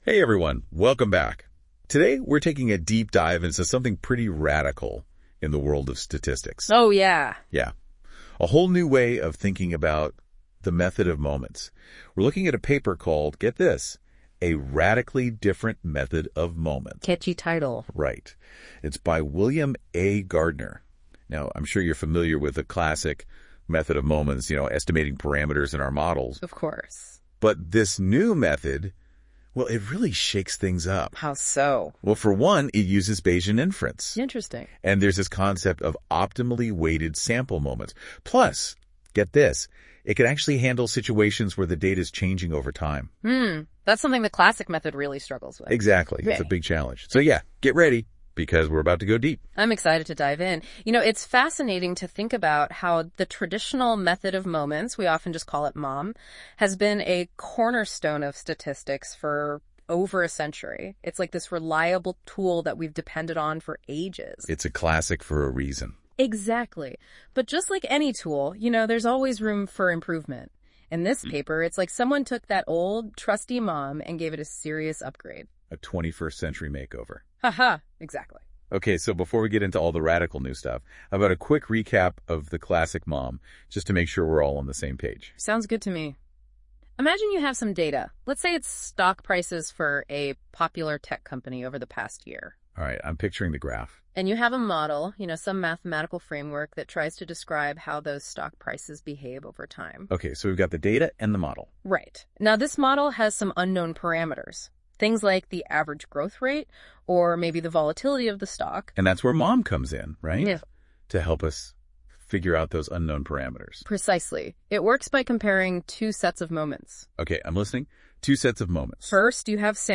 Considering the depth of technical detail in the above-linked source, the WCM has chosen to also give users access to a podcast here which provides an excellent overview in the form of an easy-to-listen-to chat between two conversationalists. This podcast was produced by AI using Google’s experimental NotebookLM.